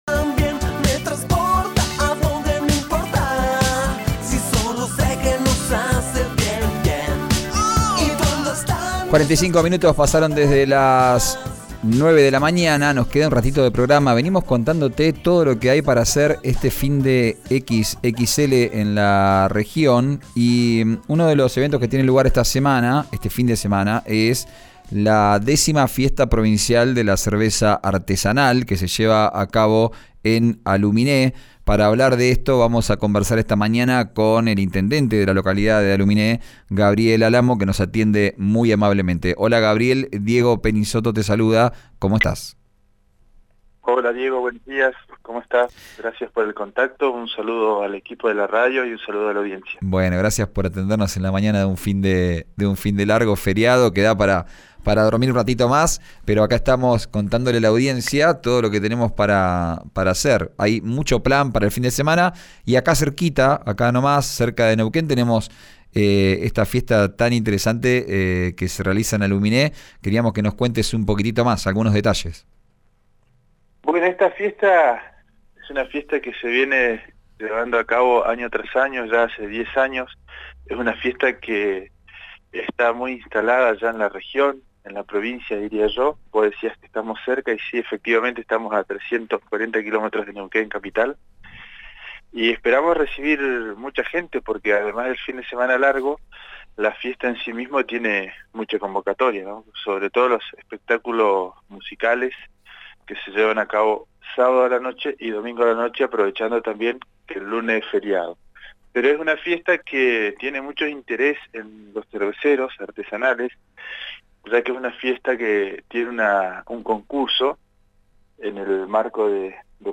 Escuchá al intendente de Aluminé, Gabriel Álamo, en RÍO NEGRO RADIO: